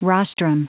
Prononciation du mot rostrum en anglais (fichier audio)
Prononciation du mot : rostrum